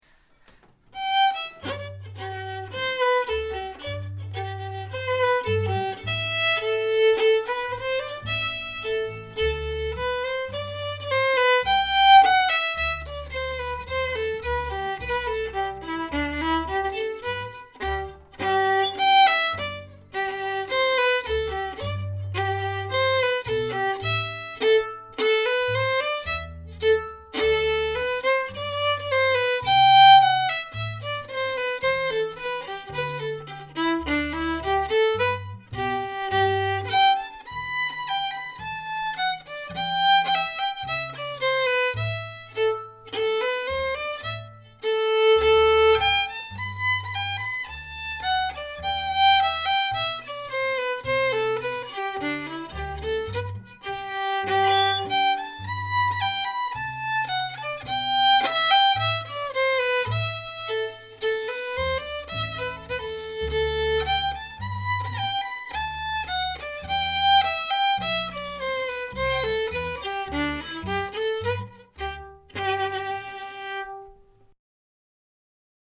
Reel - G Major